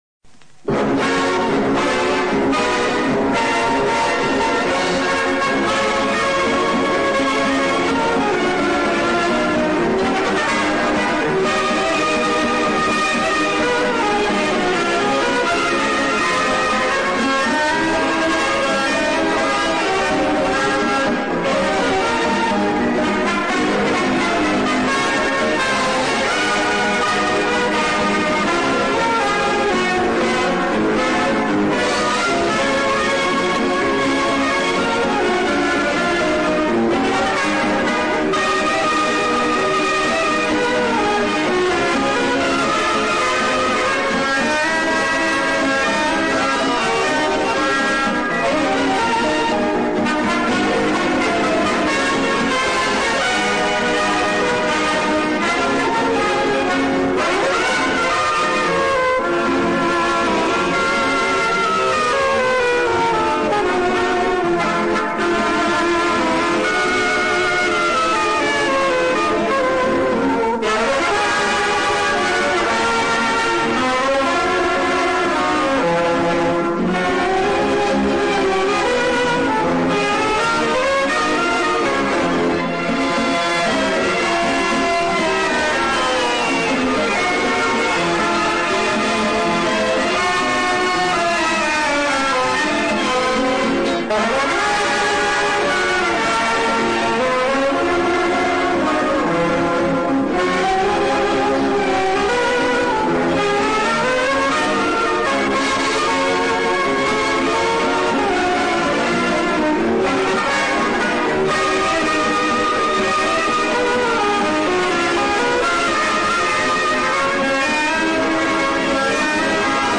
Если Вы не возражаете, я хотел бы добавить вальс, который звучал в Новогодних голубых огоньках 1962,1966 годах.